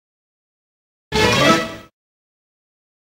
Cartoon Transition Sound